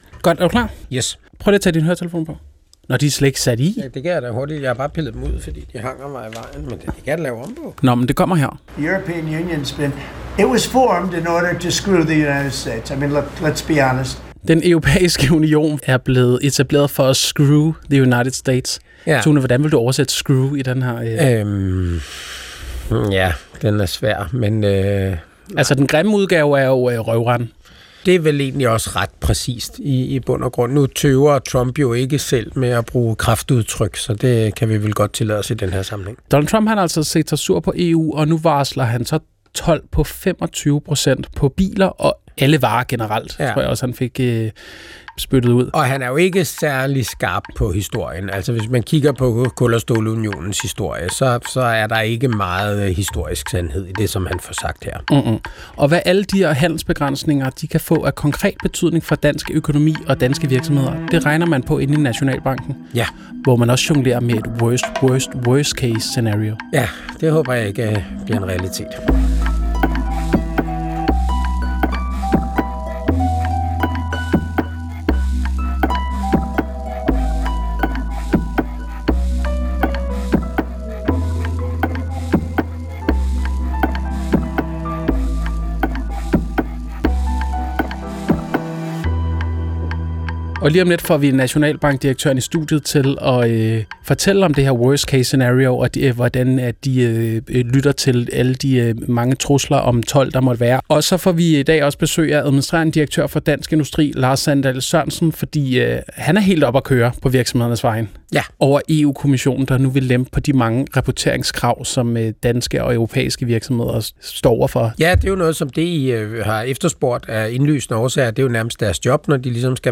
Nationalbankdirektør Signe Krogstrup kommer i studiet.